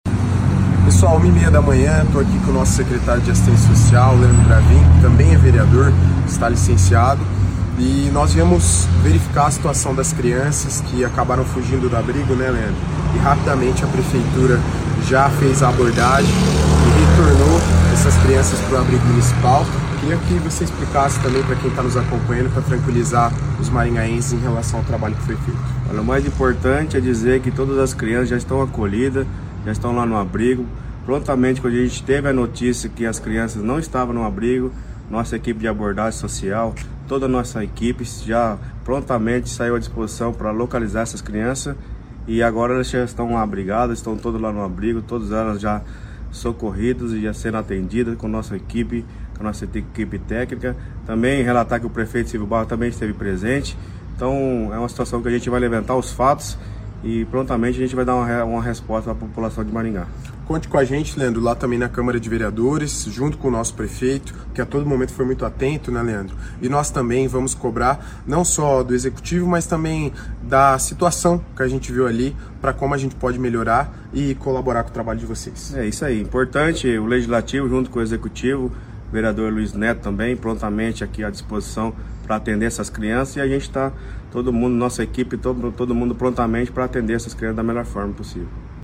Nas redes sociais, o secretário de Assistência Social, Leandro Bravin, e o vereador Luiz Neto falaram sobre o assunto.